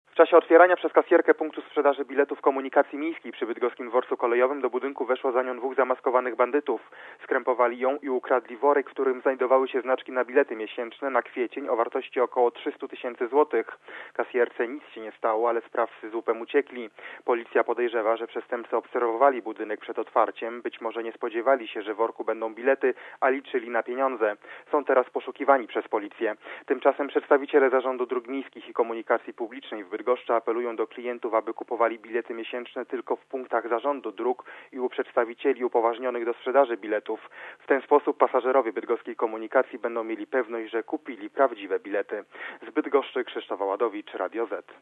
Relacja reportera Radia Zet (350Kb)